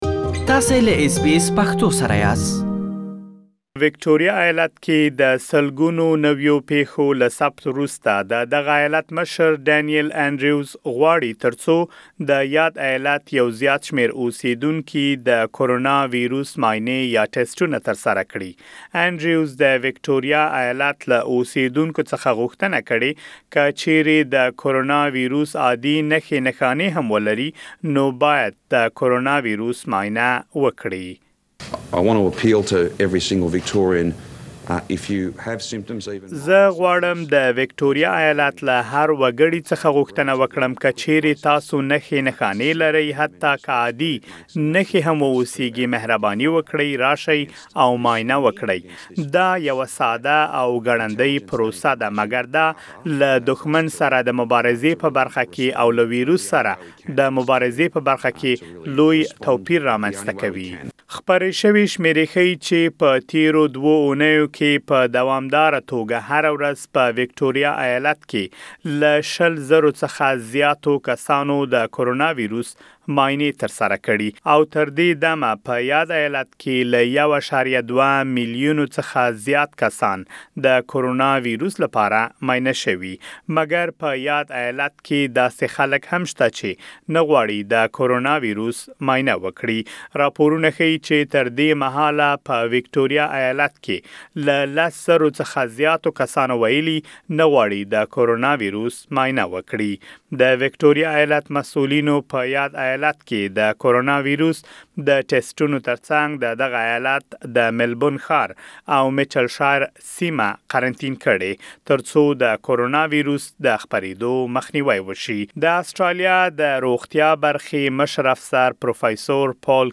تاسو کولای شئ، د ټېسټونو د زیاتوالي په اړه لا ډېر جزیات زمونږ په غږیز رپوټ کې واورئ.